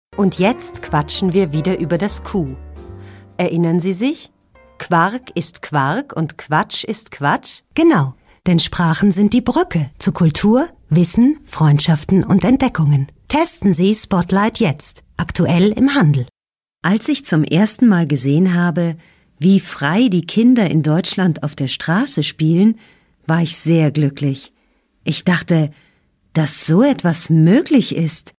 warme Stimme für Hoerbuecher, Reportagen, Dokumentationen, Voice over, Werbung, Computerspiele, Mutimediaprojekte, Sprachkurse.
Sprechprobe: eLearning (Muttersprache):